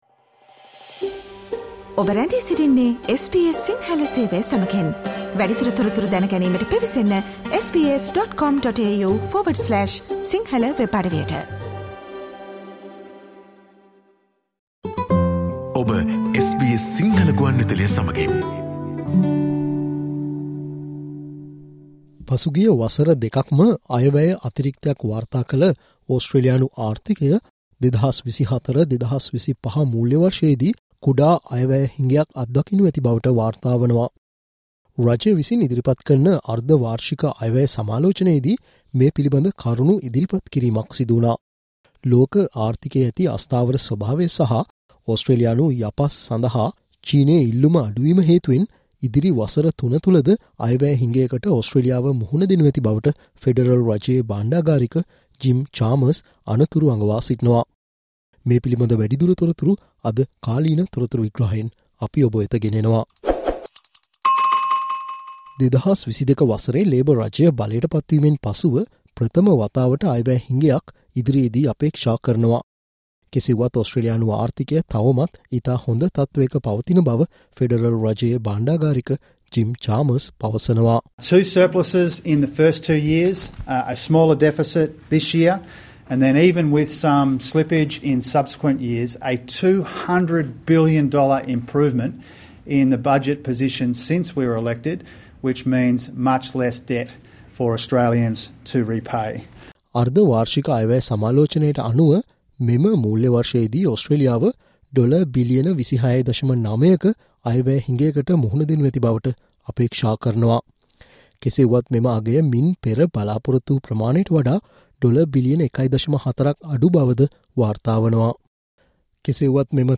Treasurer Jim Chalmers warns of budget deficits for the next three years: Explainer 19 Dec